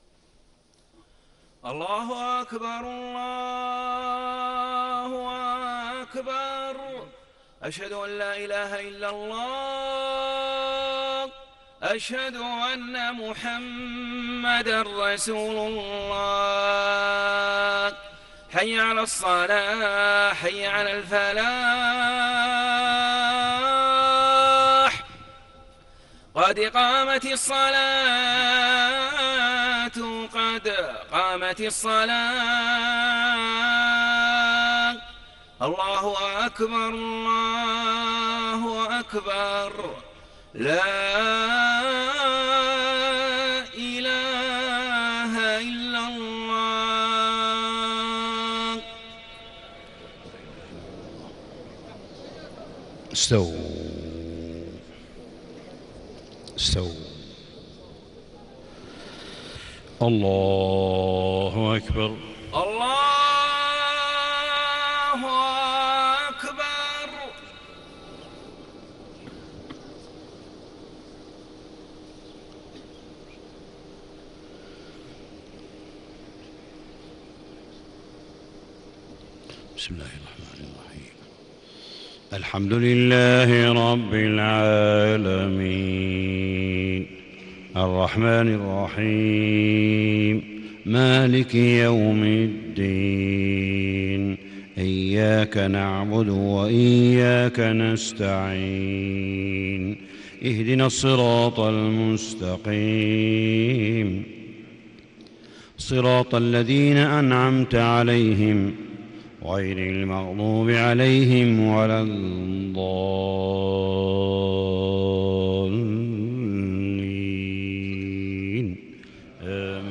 صلاة الفجر 1 شوال 1437هـ خواتيم سورتي البقرة 284-286 و الأنعام 160-165 > 1437 🕋 > الفروض - تلاوات الحرمين